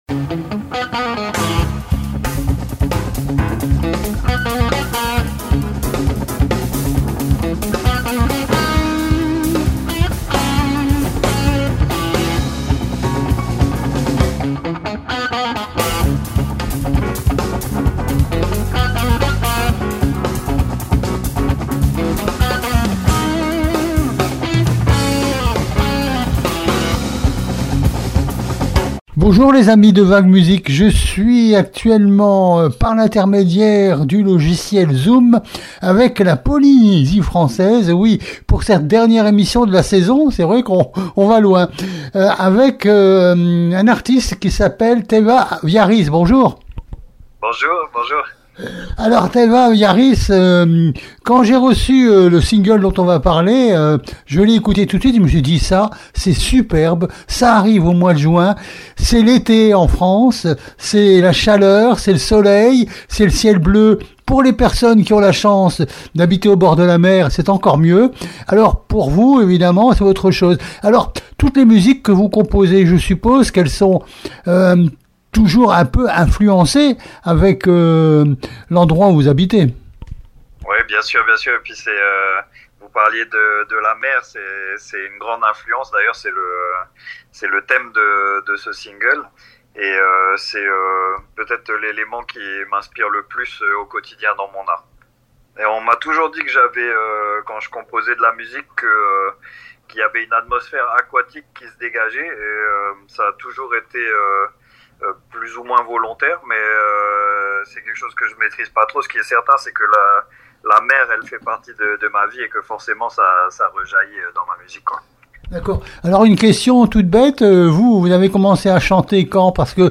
INTERVIEW DU 7 JUILLET 2025